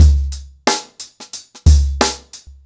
TheStakeHouse-90BPM.9.wav